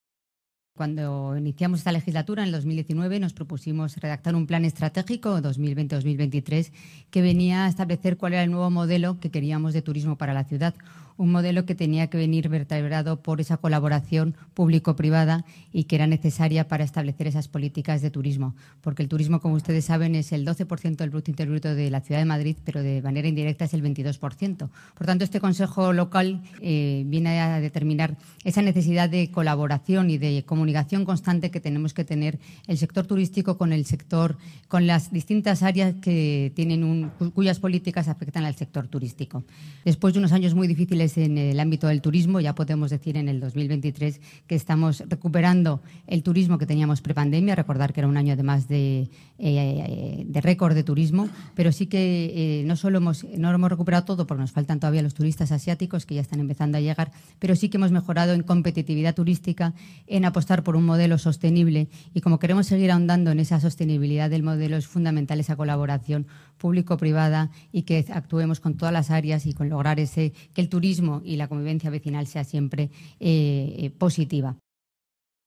Nueva ventana:Así lo ha explicado hoy la concejala delegada de Turismo, Almudena Maíllo: